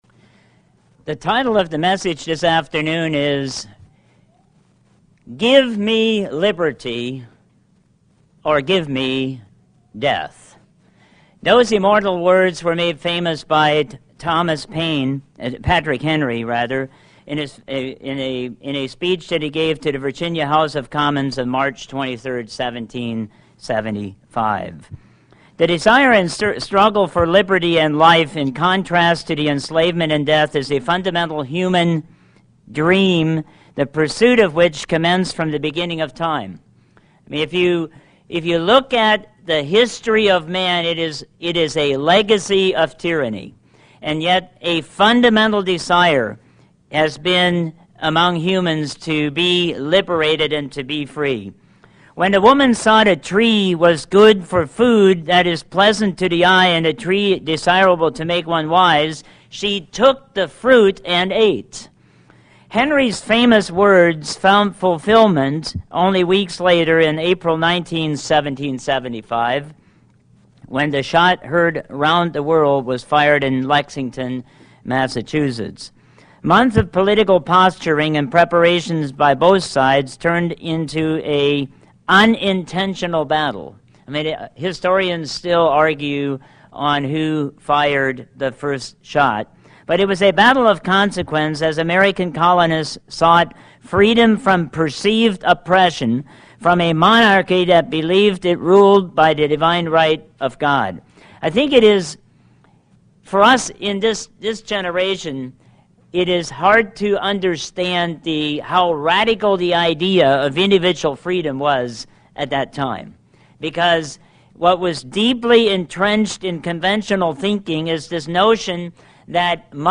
Sermons
Given in North Canton, OH